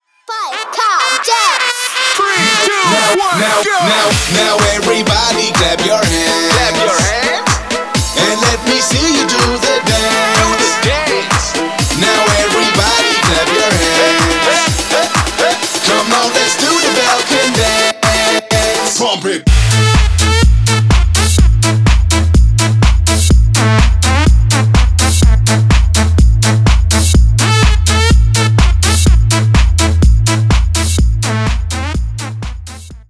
Поп Музыка
весёлые